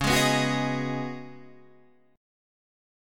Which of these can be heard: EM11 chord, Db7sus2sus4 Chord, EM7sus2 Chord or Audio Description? Db7sus2sus4 Chord